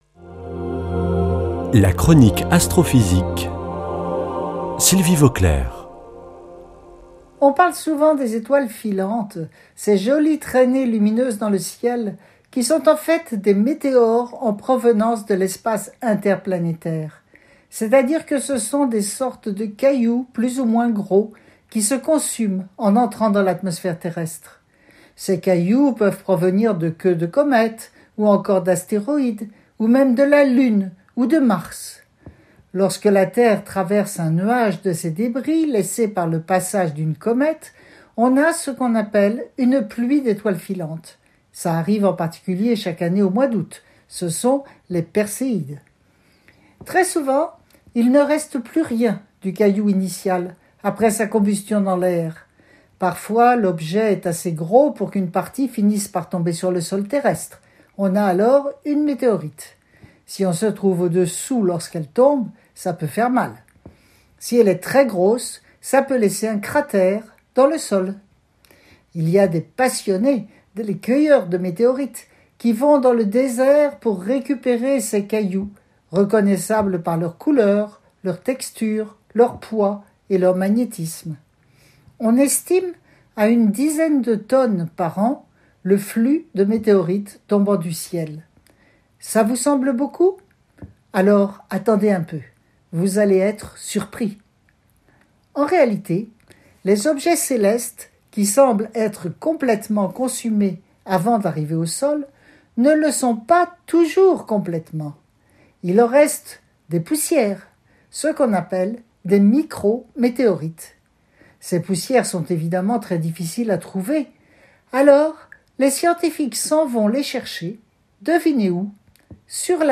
mardi 17 août 2021 Chronique Astrophysique Durée 3 min